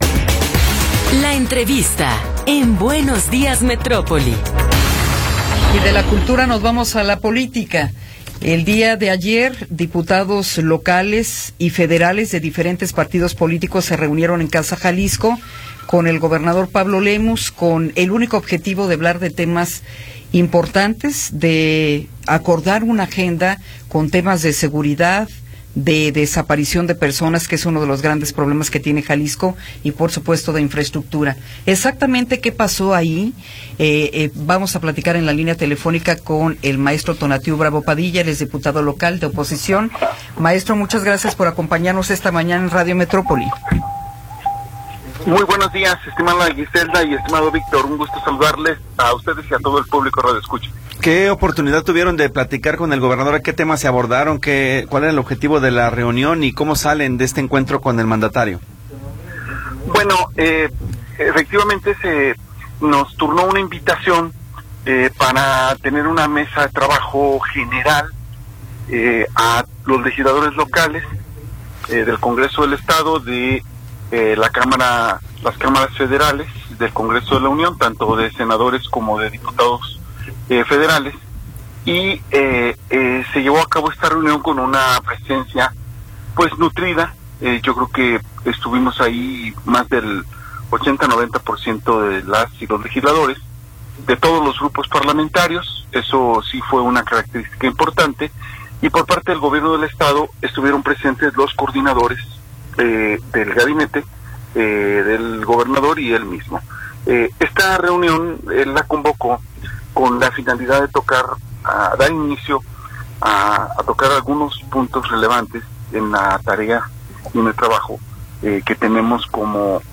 Entrevista con Tonatiuh Bravo Padilla